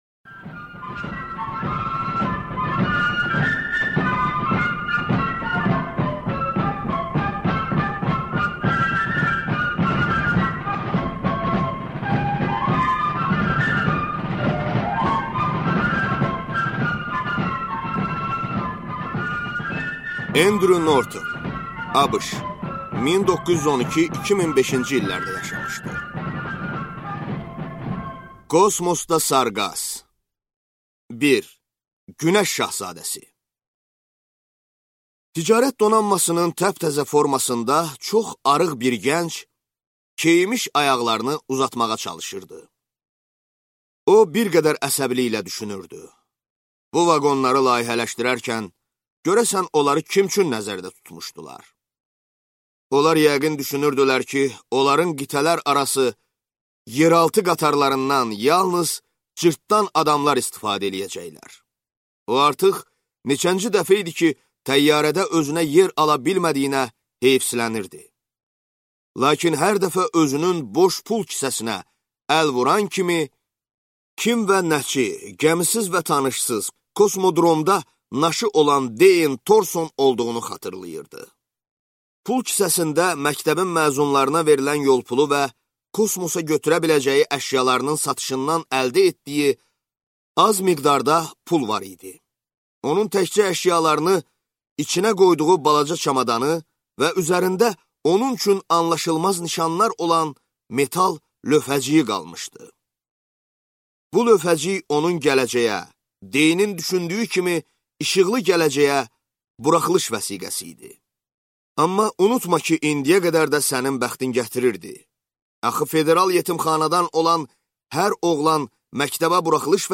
Аудиокнига Kosmosda sarqas | Библиотека аудиокниг